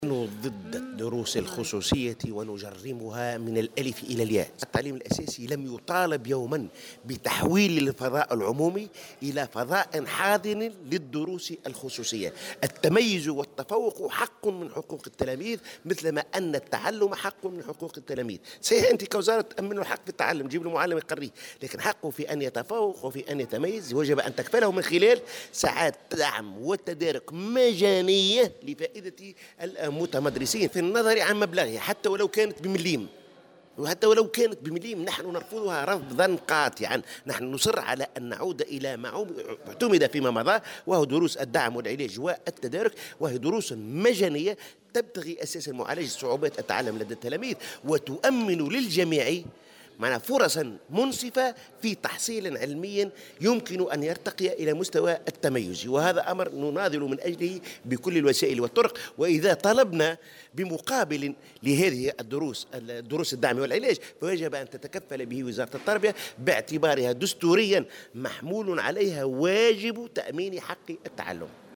وأضاف في تصريح اليوم لمراسة "الجوهرة أف أم" على هامش اختتام ندوة وطنية للتعليم الأساسي بالحمامات، أن النقابة ترفض تقديم دروس للتلاميذ بمقابل وتحويل الفضاء العمومي لفضاء حاضن للدروس الخصوصية، داعيا إلى عودة دروس الدعم والتدارك المجانية والتي تهدف إلى معالجة صعوبات التعلم وتُؤمن لجميع التلاميذ الفرص نفسها، وفق تعبيره.